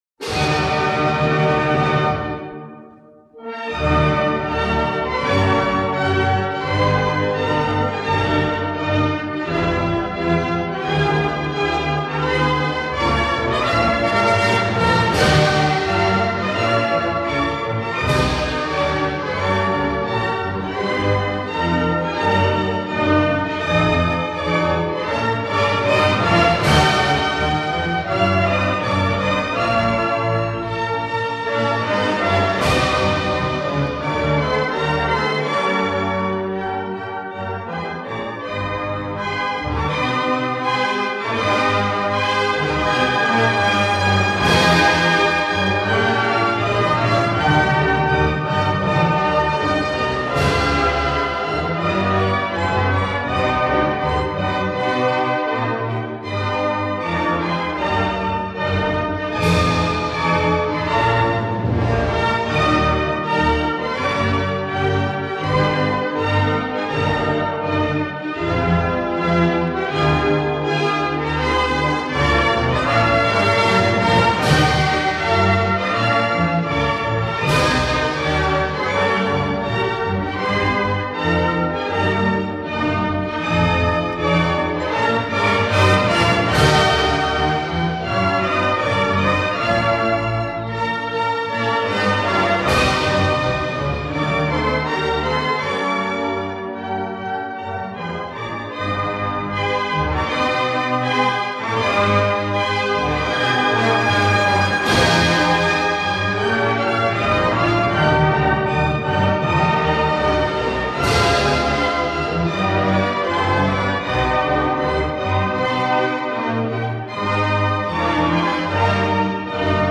в инструментальном исполнении